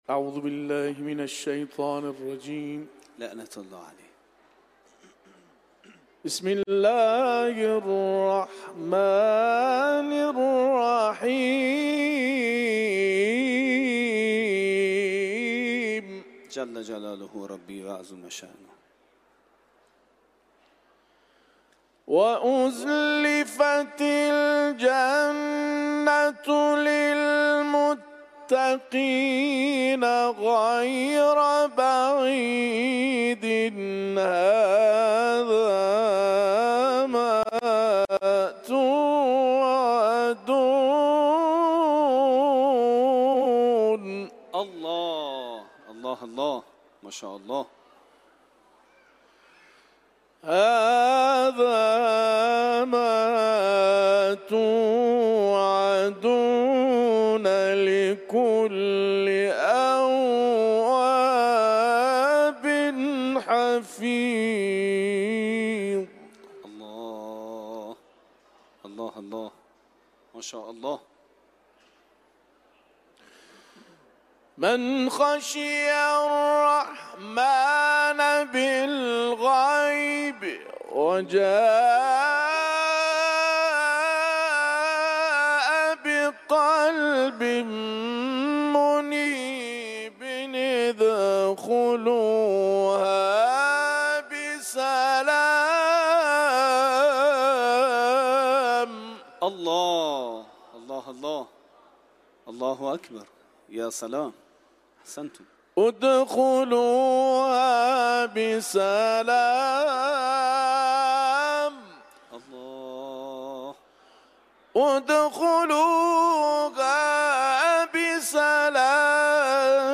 در محفل انس با قرآن حرم مطهر رضوی به اجرا رسیده است
سوره قاف ، تلاوت قرآن